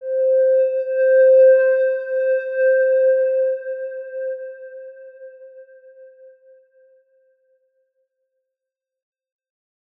X_Windwistle-C4-mf.wav